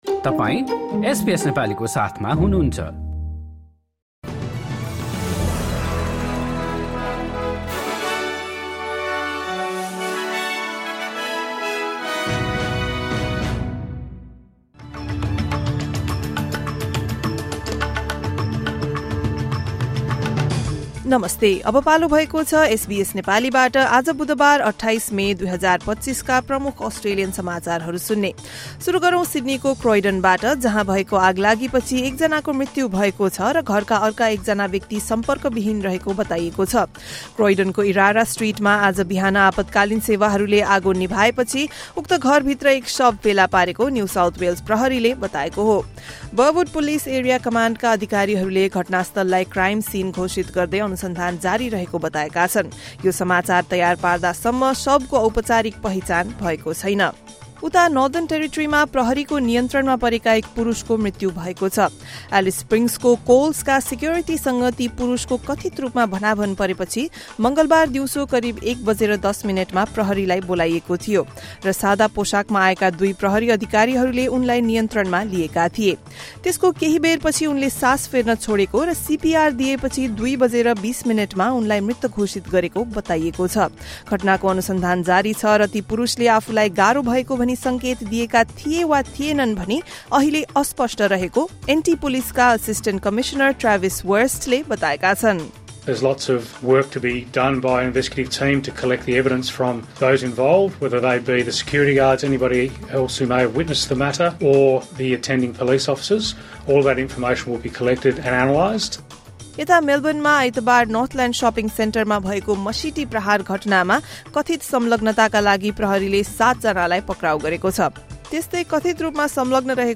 एसबीएस नेपाली प्रमुख अस्ट्रेलियन समाचार: बुधवार, २८ मे २०२५